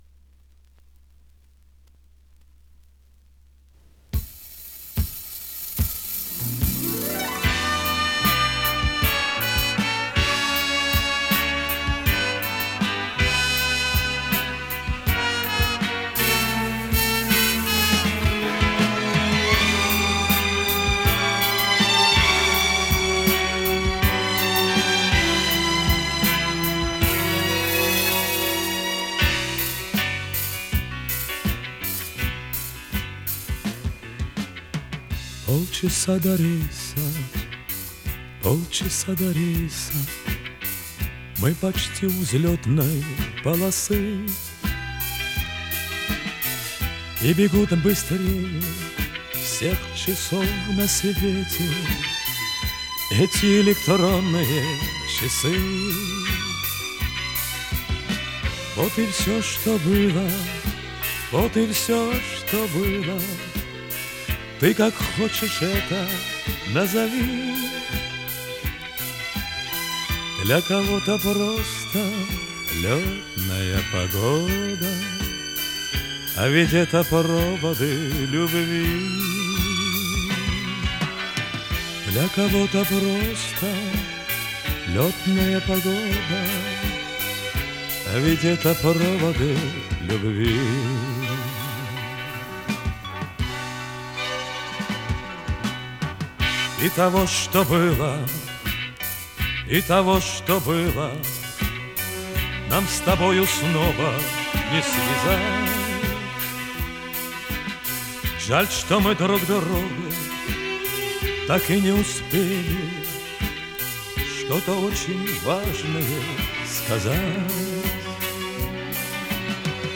Вот с винила 1980